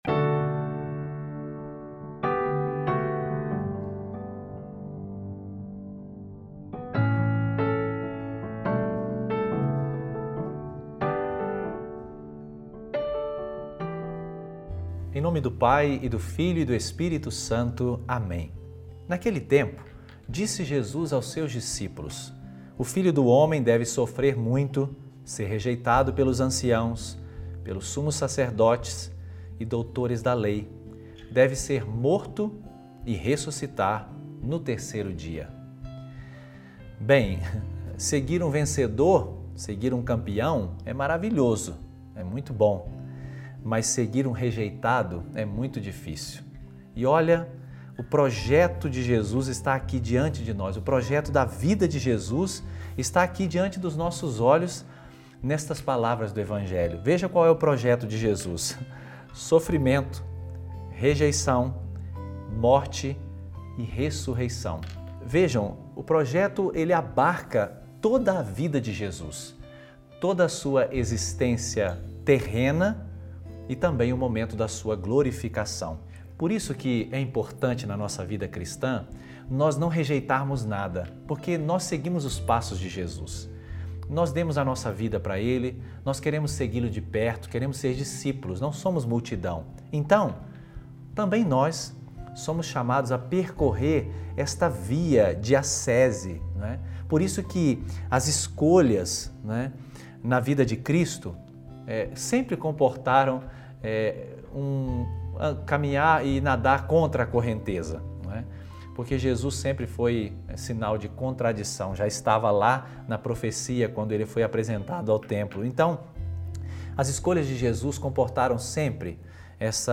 Reflexão sobre o Evangelho